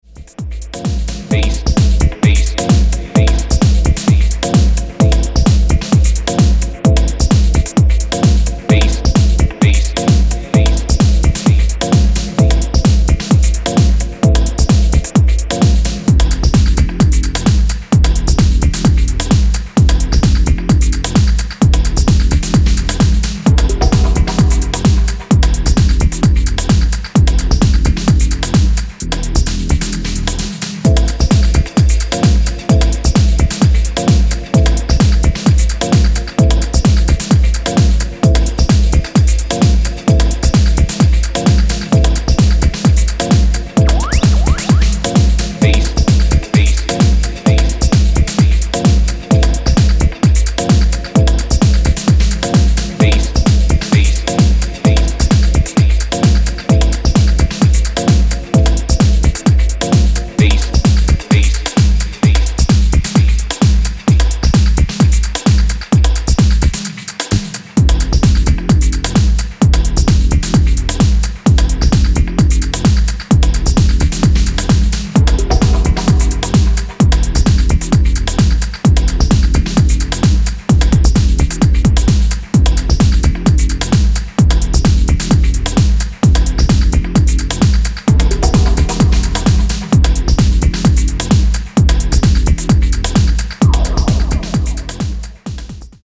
headstrong house, techno, and post speed garage bass lines
House Techno